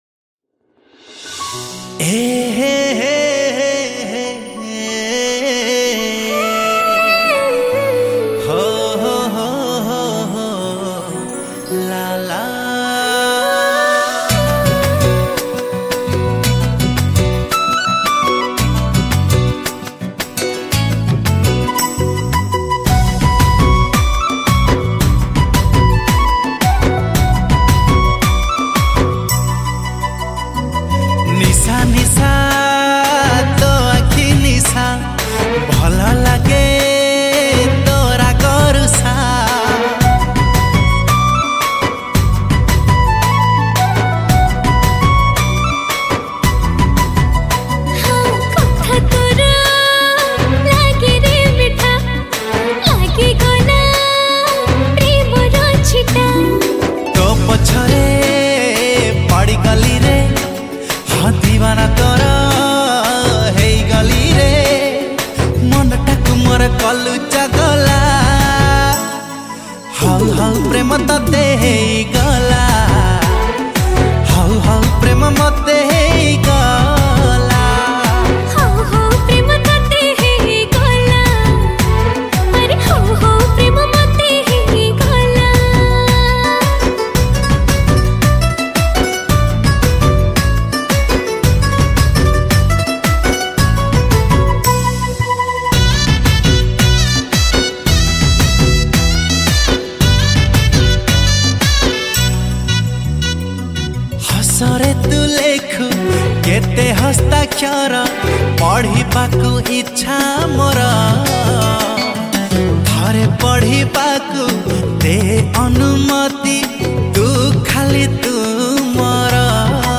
Full Studio Version